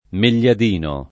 Megliadino [ mel’l’ad & no ]